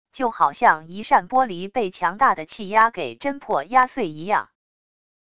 就好像一扇玻璃被强大的气压给真破压碎一样wav音频